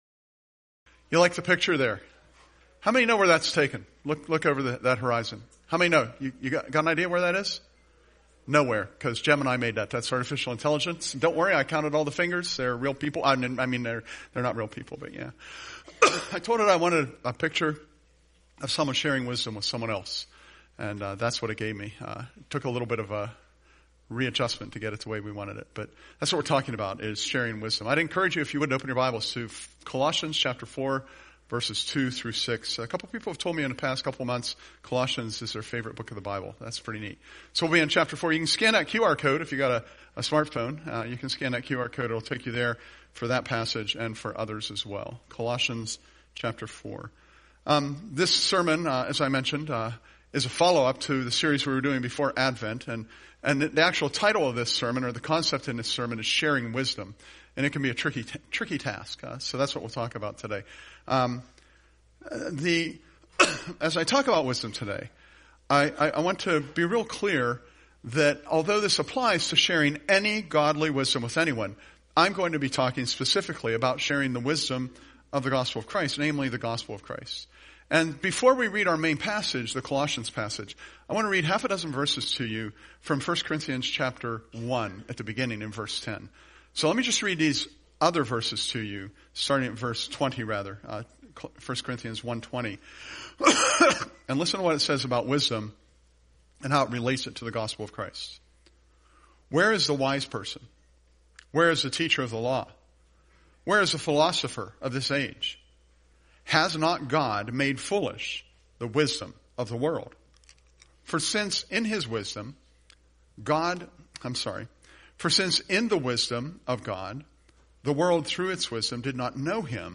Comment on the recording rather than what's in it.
Presented at Curwensville Alliance on 1/18/26